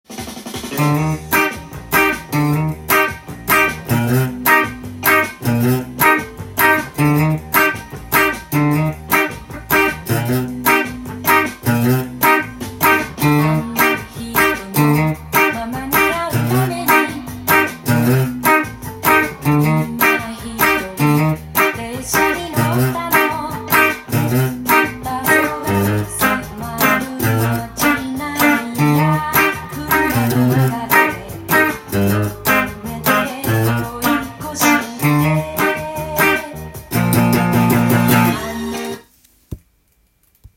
ギターTAB譜
音源に合わせて譜面通り弾いてみました
２弦３弦を弾くというシンプルなアレンジなっています。